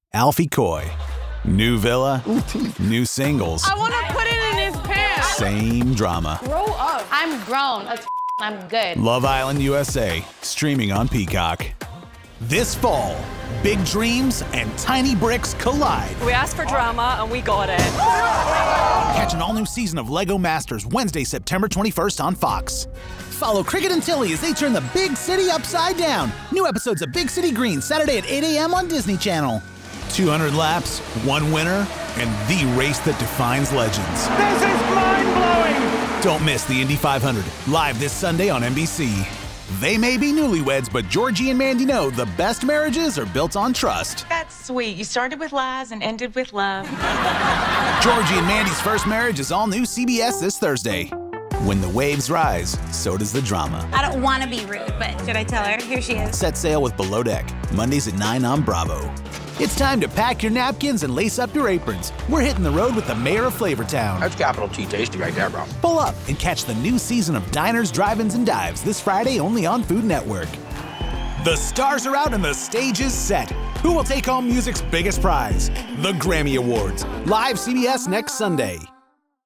Friendly, energetic, familiar, and funny. Director tested. Client approved.
Nondescript American • New York City • Coastal/Lowland Southern • Upper Midwestern • Midland American • Western American
Young Adult
My strong suits include the casual and conversational reads that sound like they're coming from your best friend, the enthusiastic and excited reads that hype up audiences, and well-honed comedic timing for your sharp-witted copy that comes across effortlessly.